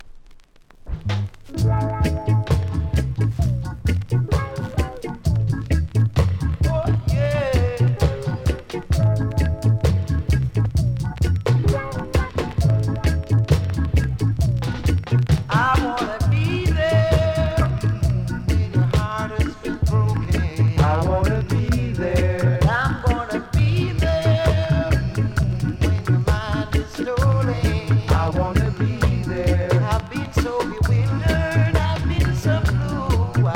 REGGAE 70'S
キズそこそこあり（後半スタンパー起因もあり）、所々ノイズ感じますがキズの割りには少なく思います。